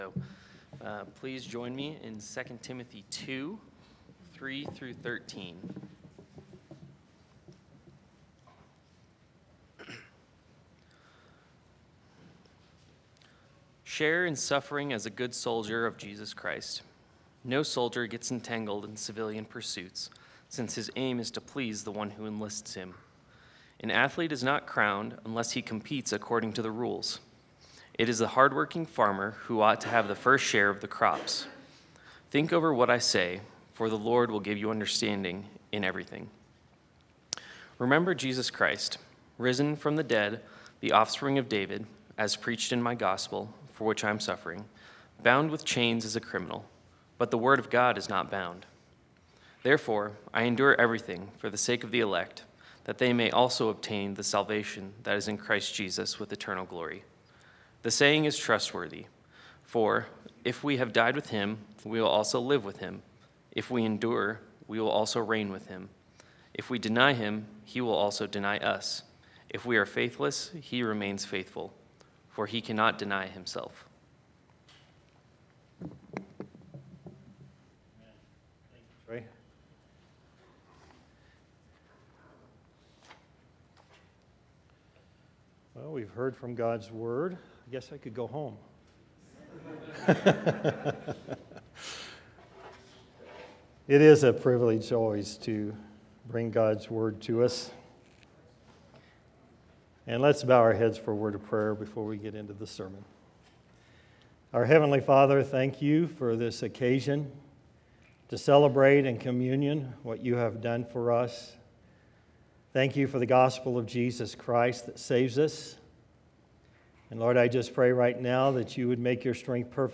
Sermons | First Baptist Church of Golden
Guest Speaker